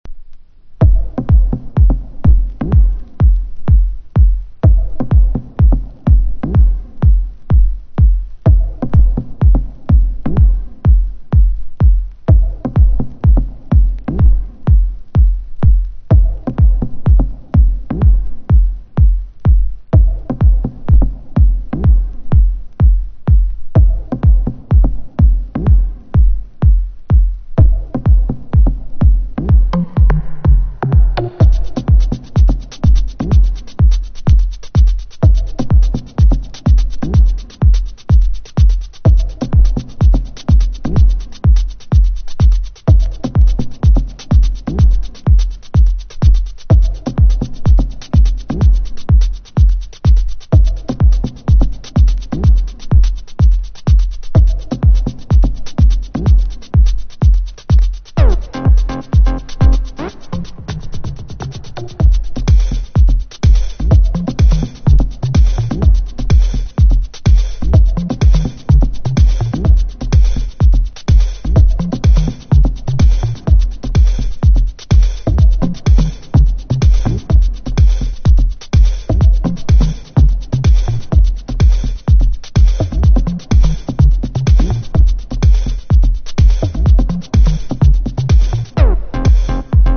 不穏なシンセの音色も、その危険度に輪をかけるプログレッシブ・トラック